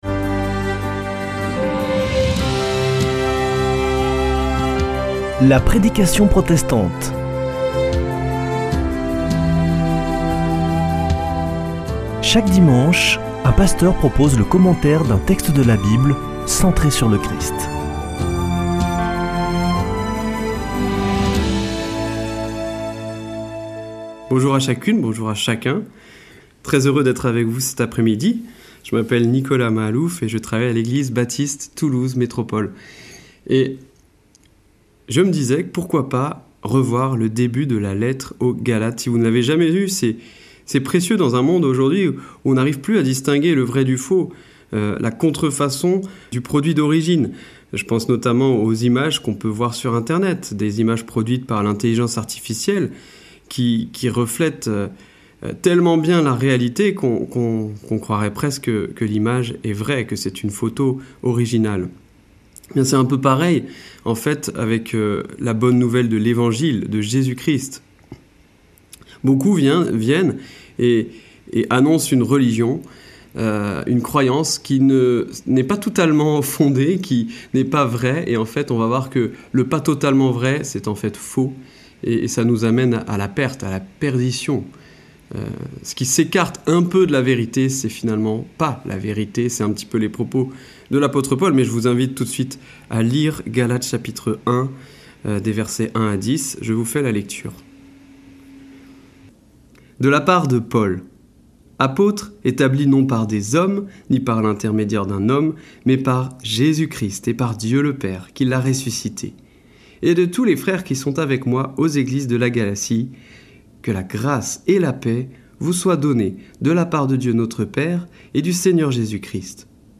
Accueil \ Emissions \ Foi \ Formation \ La prédication protestante \ Aucune autre nouvelle n'est aussi bonne !
Partager Copier ce code (Ctrl+C) pour l'intégrer dans votre page : Commander sur CD Une émission présentée par Des protestants de la région Présentateurs Voir la grille des programmes Nous contacter Réagir à cette émission Cliquez ici Qui êtes-vous ?